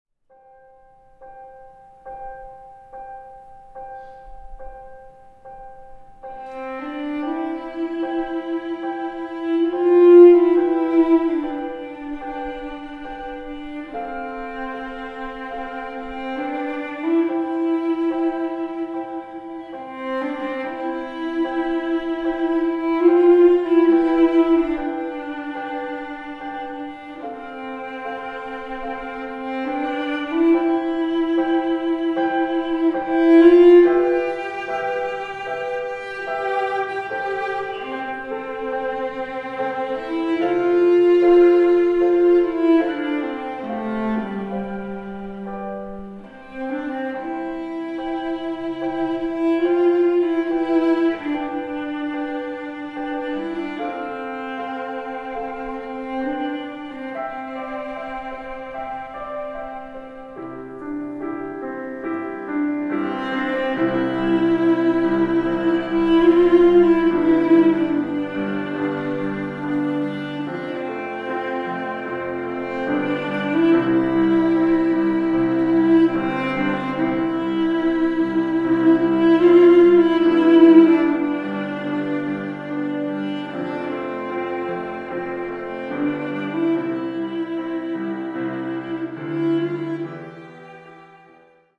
populäre Musik